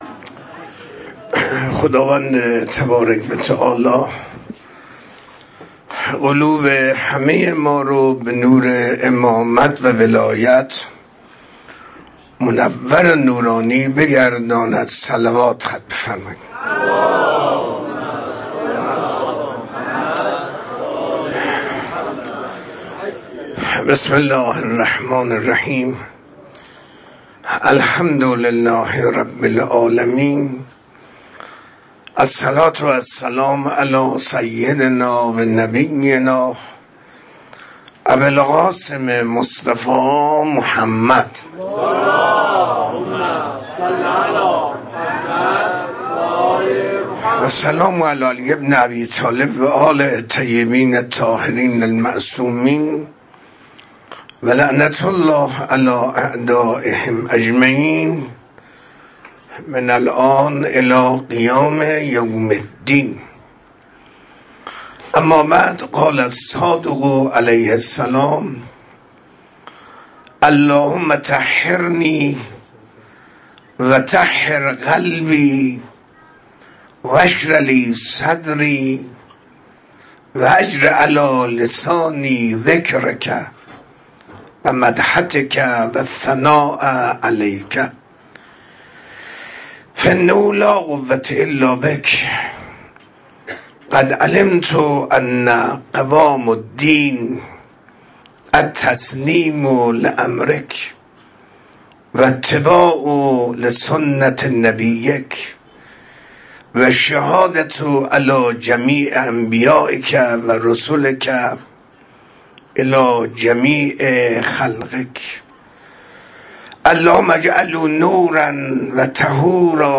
منبر 22 مرداد 1404، منزل استاد